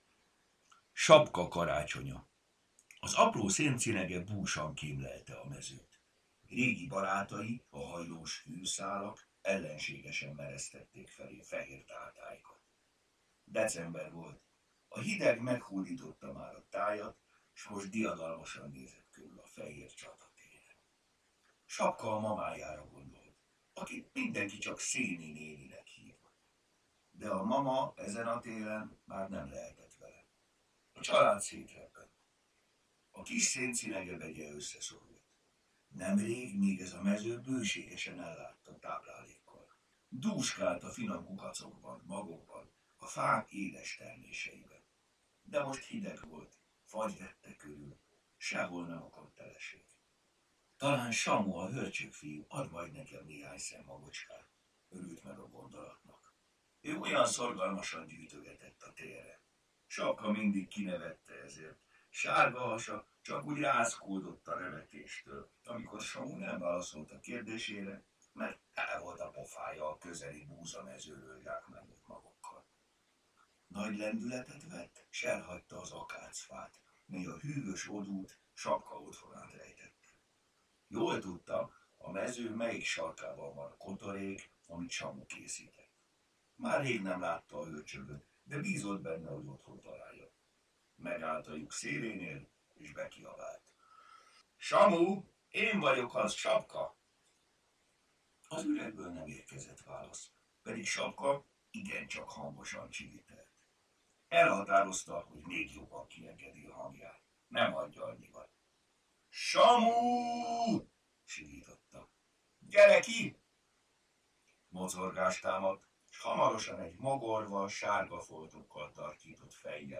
Hangos mese: Sapka Karácsonya Mindet meghallgatom ebből a folyamból!